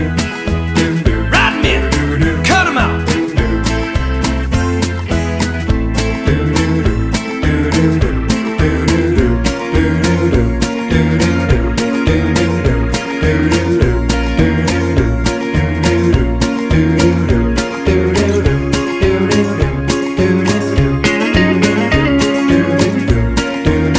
One Semitone Down Soundtracks 2:33 Buy £1.50